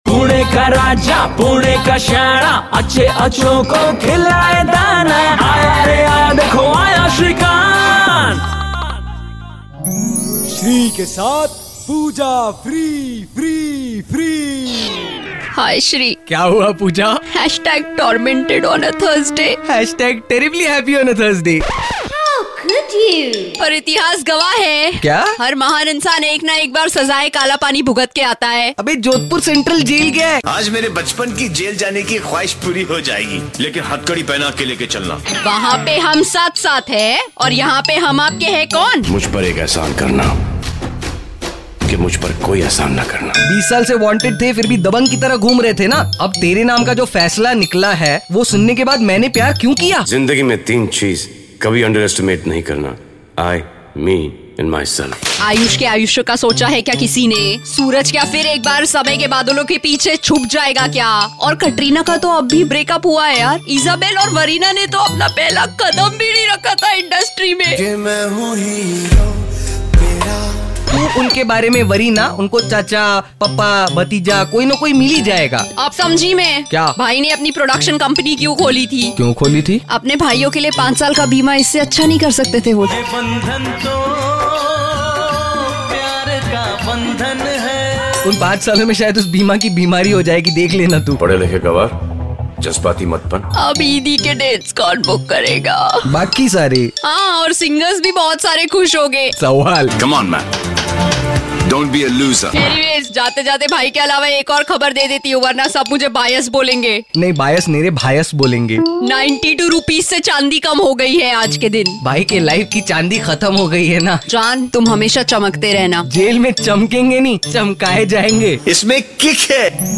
CLCIK & LISTEN TO THIS HILARIOUS CONVERSATION BETWEEN THEM